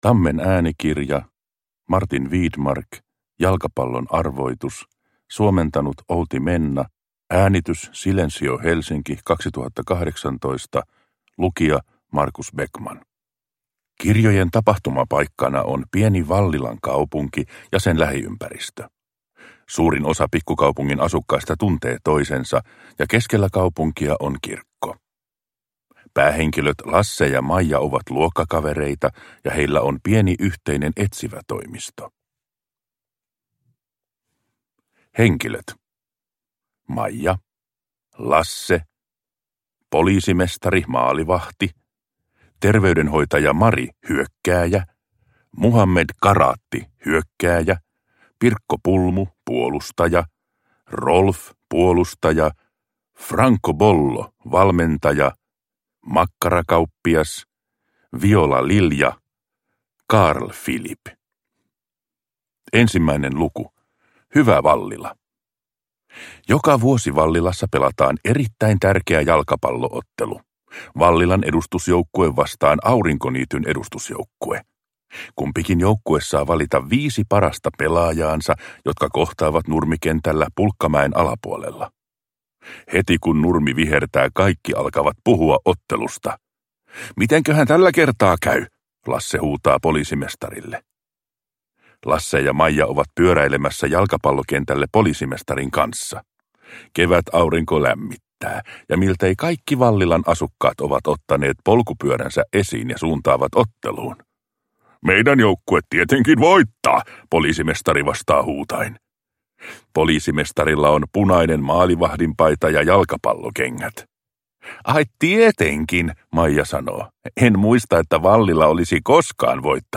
Jalkapallon arvoitus. Lasse-Maijan etsivätoimisto – Ljudbok